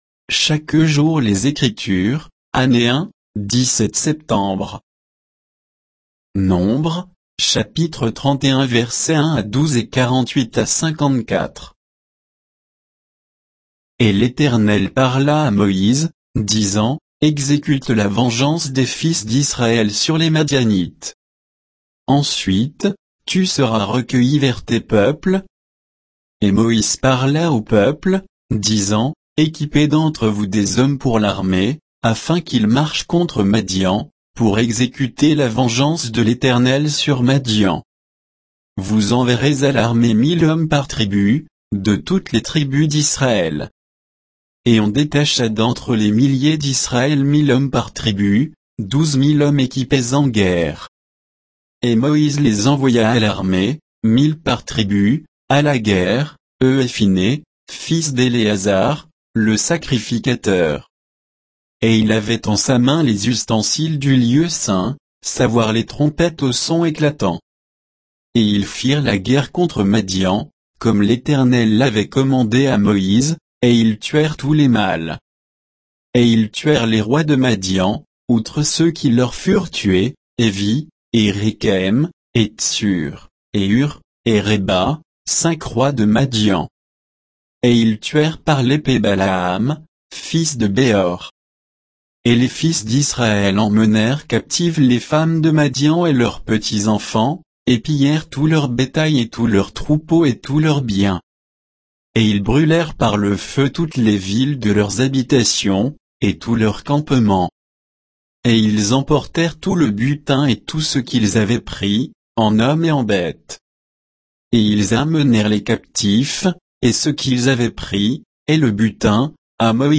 Méditation quoditienne de Chaque jour les Écritures sur Nombres 31, 1 à 12, 48 à 54